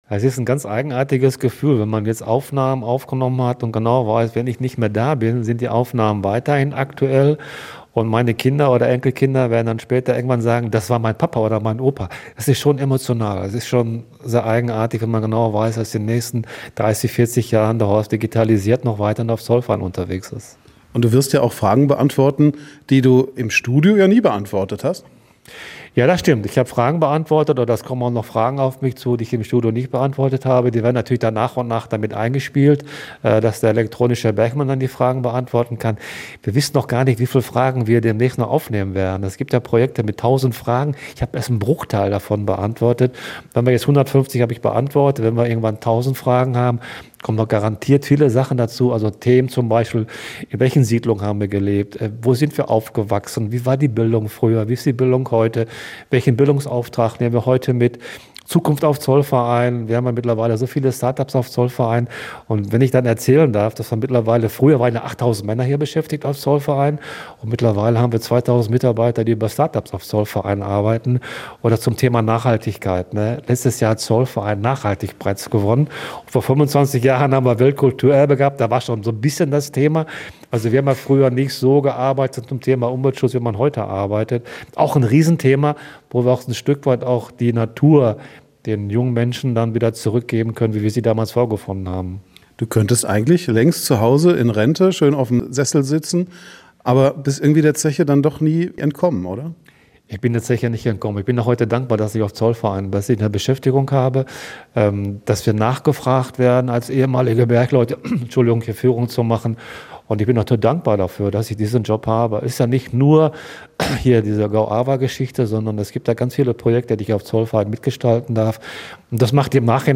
im Gespräch mit Gästeführer